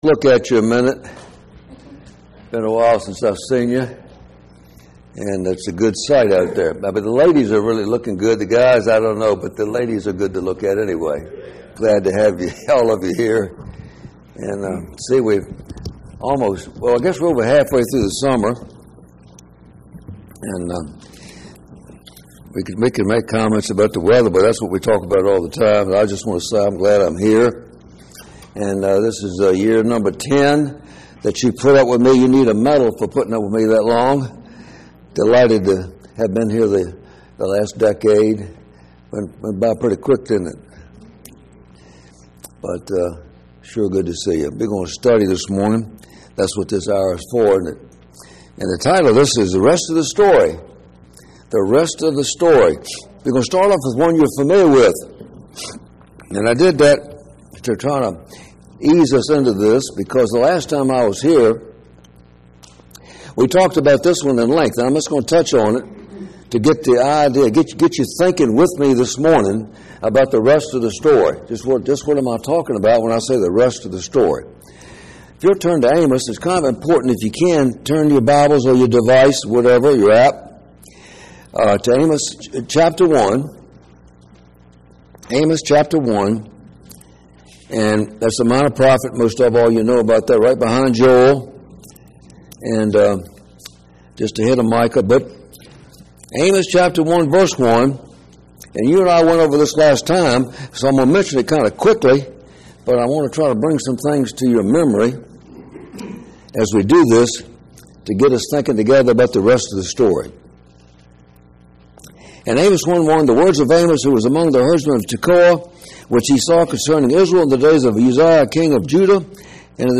7-20-25 Bible Study – The Rest of the Story